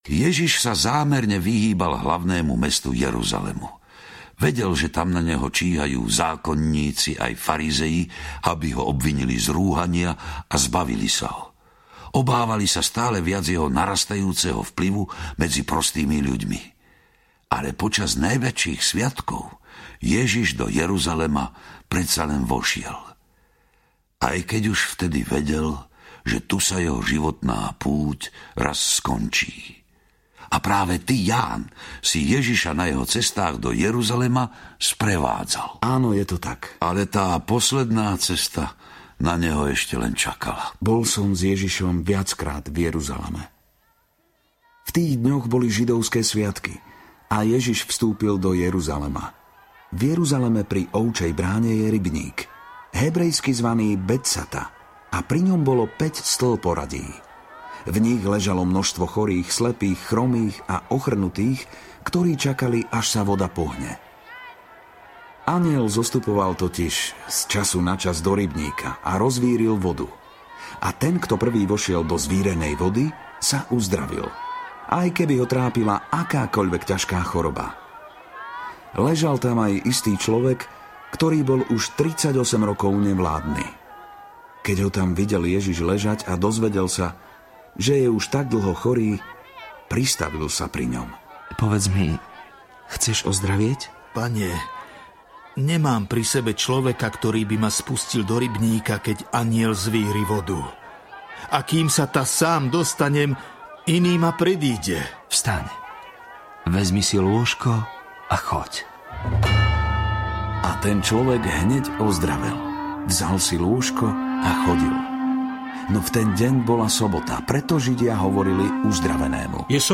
Biblia - Život Ježiša 2 audiokniha
Biblia - Život Ježiša 2 - dramatizované spracovanie Biblie podľa Nového zákona.
Ukázka z knihy